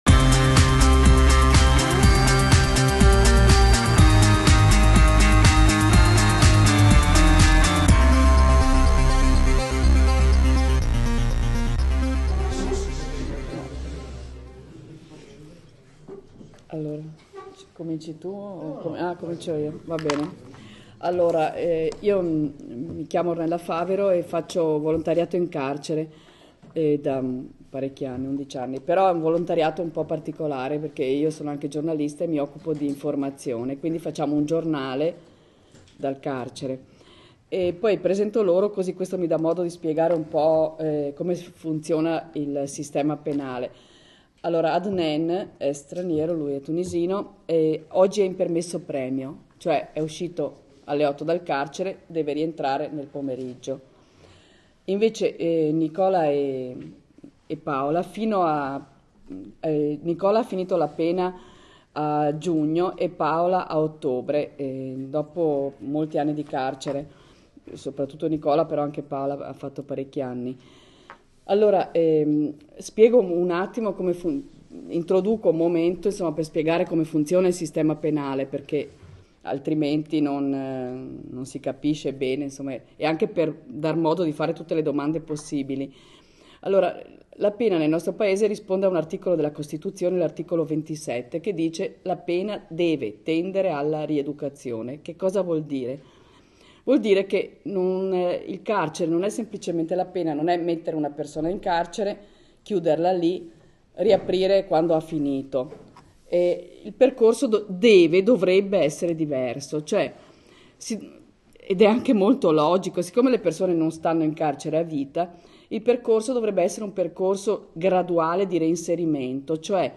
Le classi 5E, 5F e 5G Aeronautica, hanno incontrato, presso l’ auditorium della succursale dell’ I.T.I.S. G. Natta, il gruppo di volontari ed ex detenuti della redazione di Ristretti Orizzonti, periodico dal carcere “ Due Palazzi” di Padova.